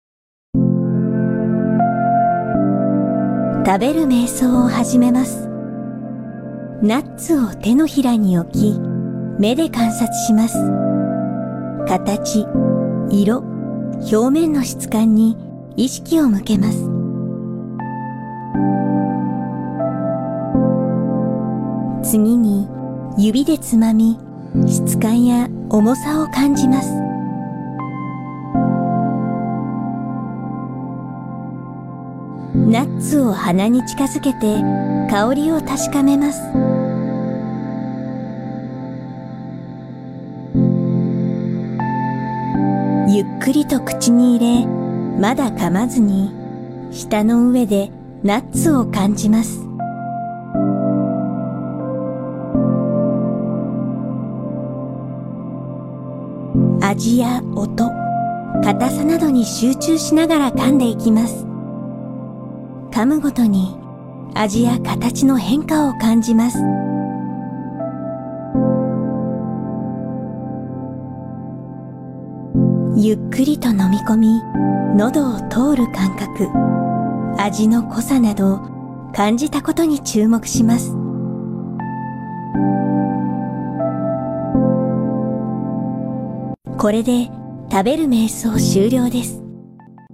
ガイダンスを聞きながらナッツを一粒食べる瞑想
サンプル用食べる瞑想.mp3